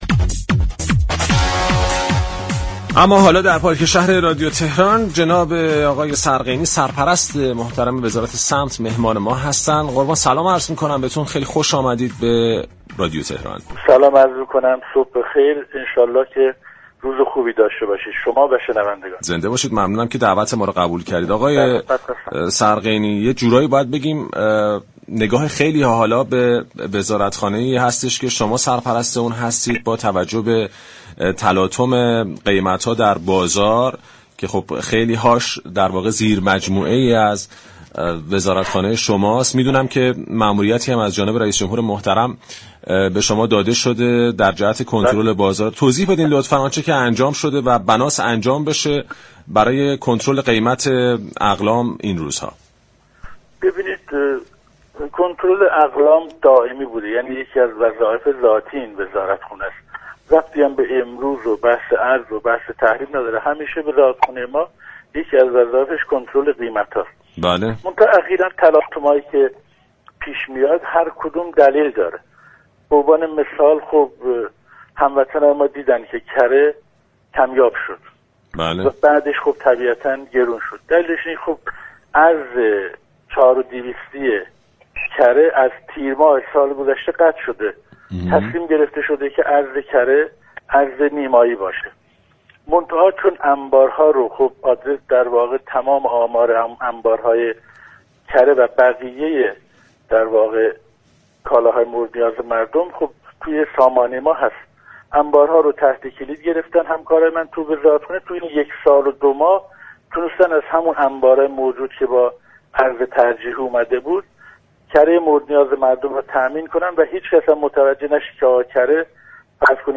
جعفر سرقینی سرپرست وزارت صمت در گفتگو با برنامه پارك شهر تهران از متعادل شدن قیمت برخی كالاهای اساسی طی هفته آتی خبر داد.